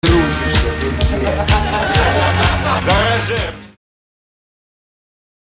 • Качество: 320, Stereo
мужской голос
Интересное смс уведомление